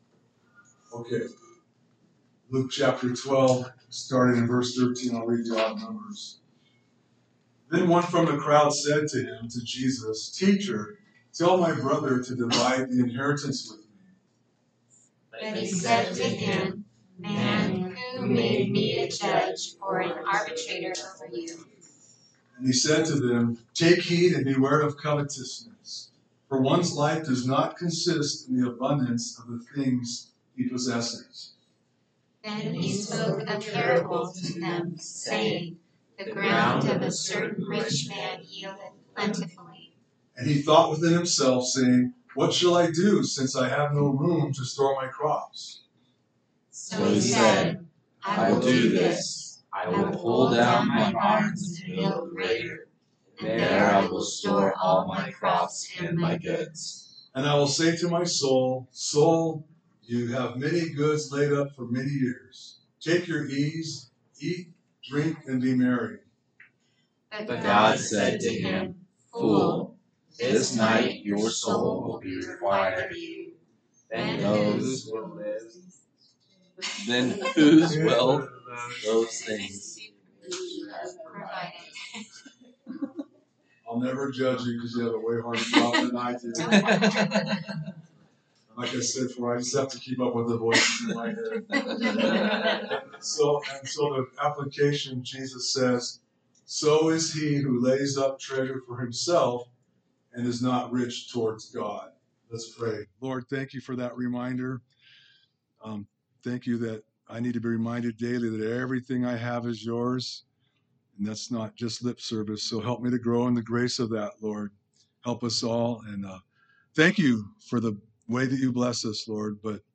A message from the series "Revelation."